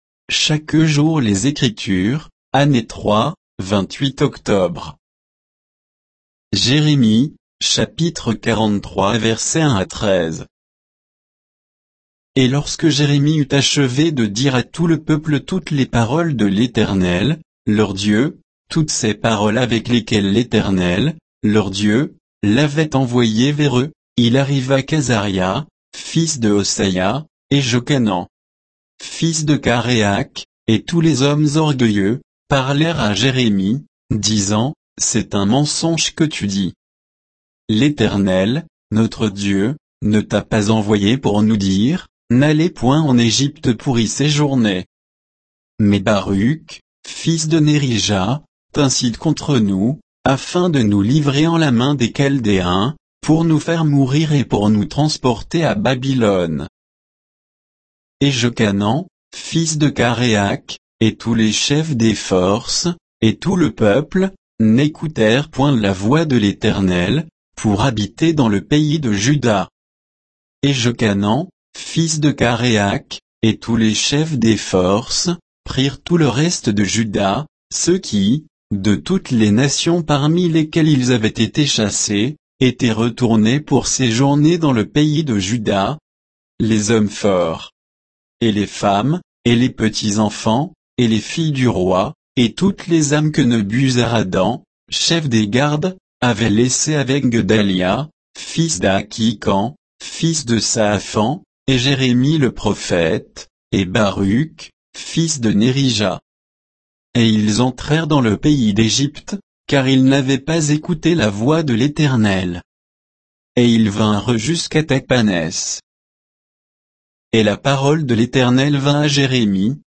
Méditation quoditienne de Chaque jour les Écritures sur Jérémie 43